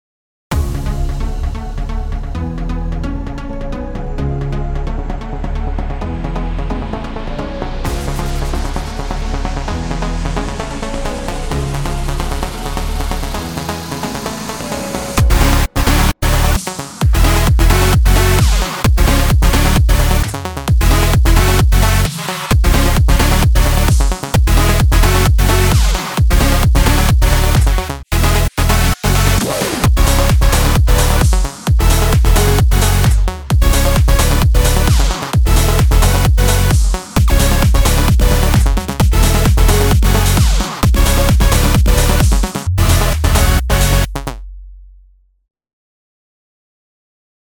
מטורף, מה זה הסיידצין הפסיכי הזה, לדעתי כדאי להחליף את ההיי הט בסוף ליותר חלש כי שמעו אותו מידי חזק, אבל פשוט טירוף!